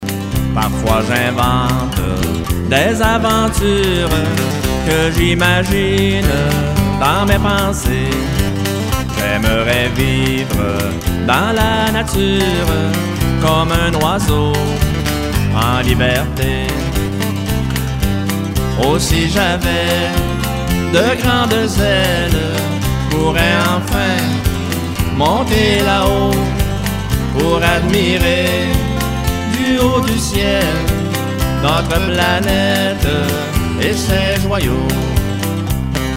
Enregistrement au studio